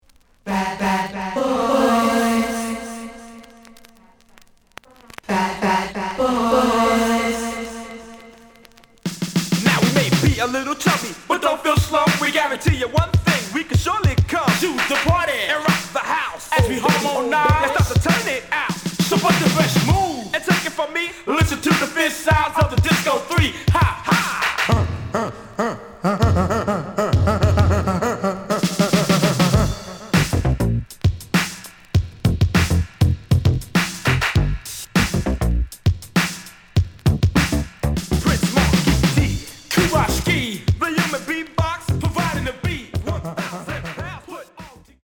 The audio sample is recorded from the actual item.
●Genre: Hip Hop / R&B